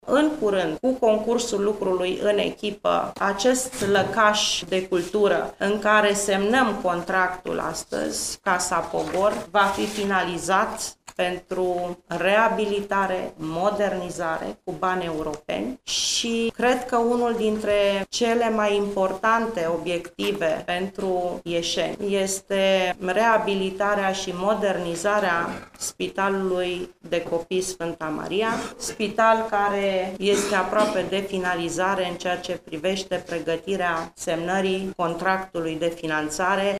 Ministrul Fondurilor Europene, Rovana Plumb: